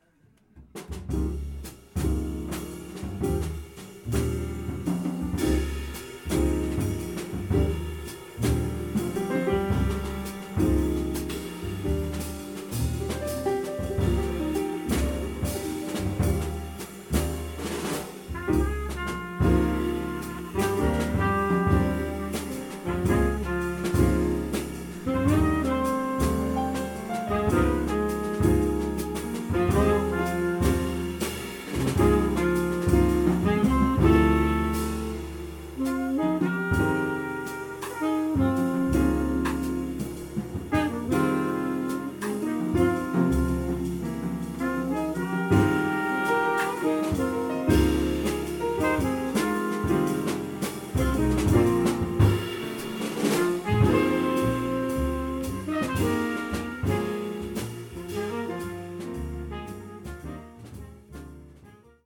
trumpet
sax
piano
bass
drums